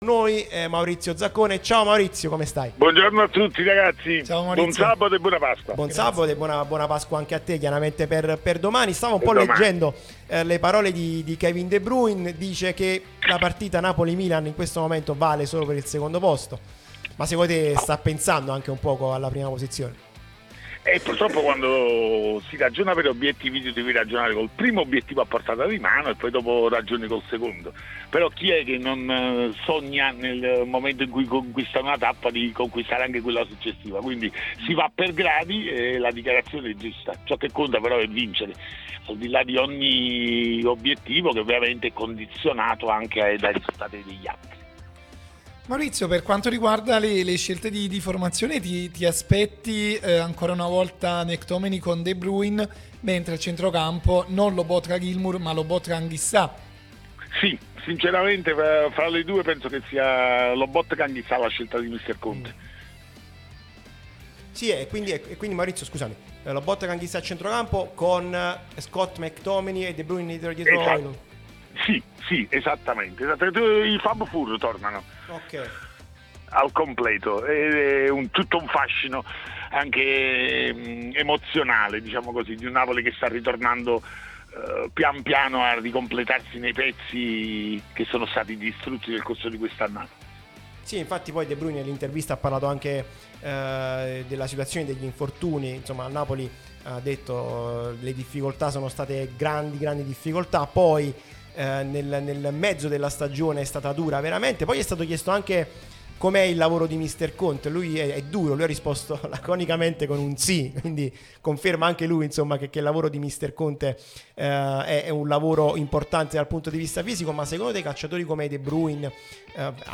prima radio tematica sul Napoli